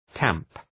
{tæmp}